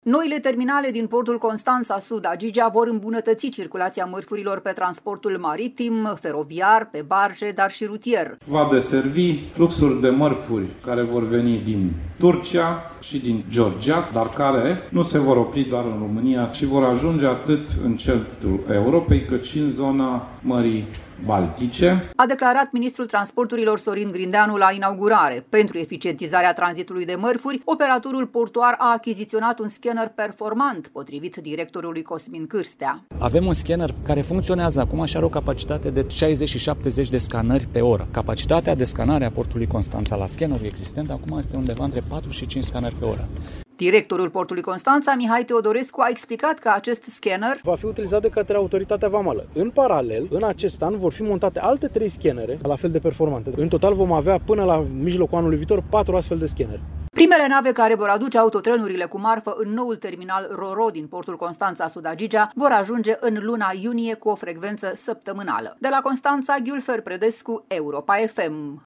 „Va deservi fluxul de mărfuri care vor veni din Turcia și din Georgia, dar care nu se vor opri doar în România ci vor ajunge atât în centrul Europei cât și în zona Mării Baltice”, a declarat ministrul Transporturilor, Sorin Grindeanu, la inaugurare.